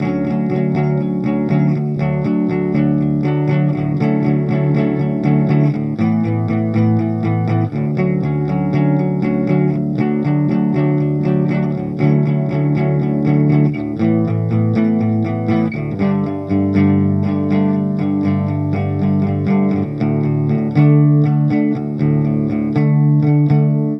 描述：Grunge摇滚吉他循环
Tag: 120 bpm Rock Loops Guitar Electric Loops 2.02 MB wav Key : D